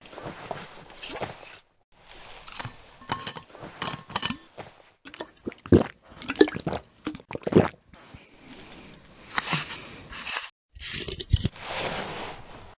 water_flask.ogg